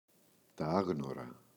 άγνωρα, τα [Ꞌaγnora]